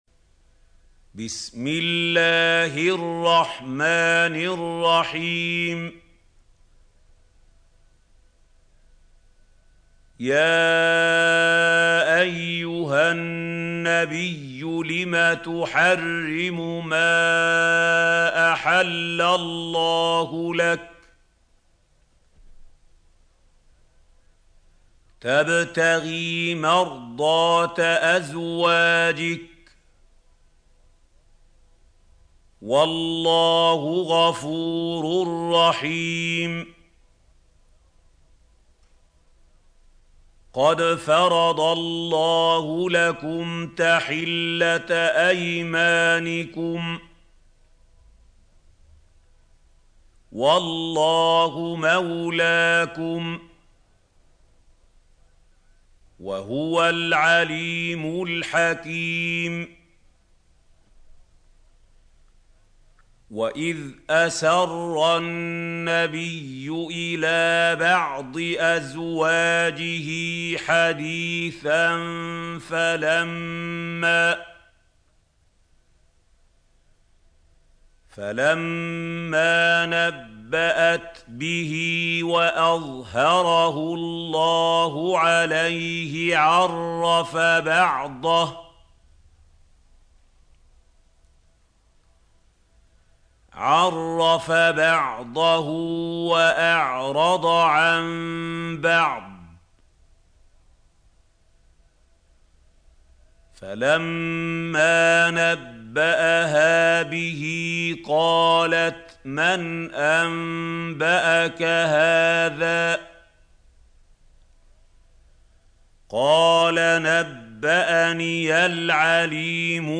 سورة التحريم | القارئ محمود خليل الحصري - المصحف المعلم